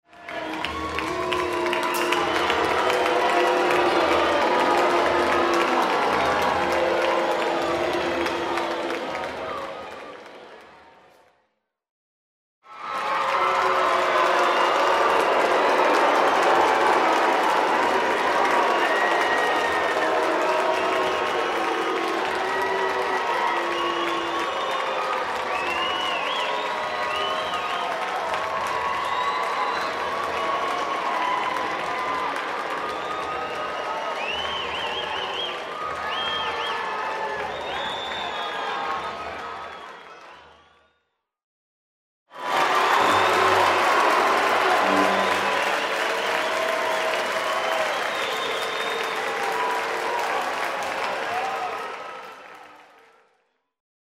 На этой странице собраны звуки, которые ассоциируются с чувством позора: смущенное бормотание, нервный смешок, вздохи разочарования.
Унылая толпа